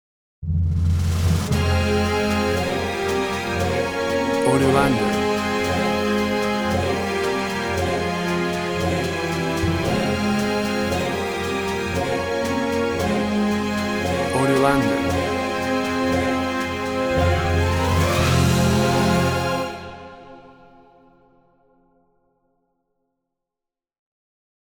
Final sixth orchestral version.
Tempo (BPM) 115